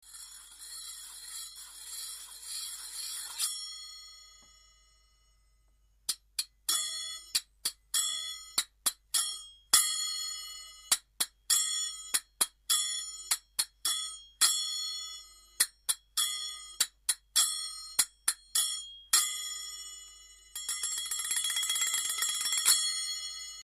ミニシンバル｜手づくり楽器 ～ 音 遊 具 ～